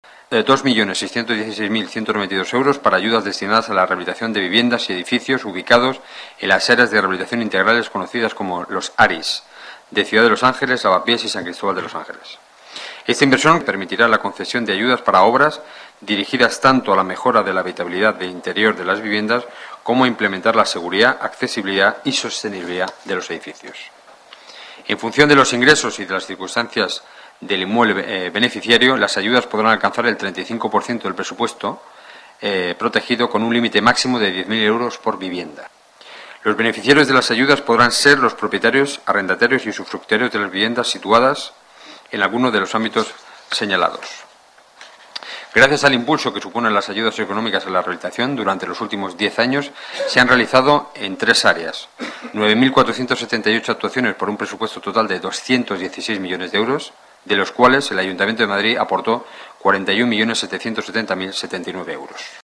Nueva ventana:Declaraciones de Enrique Núñez, portavoz del Gobierno municipal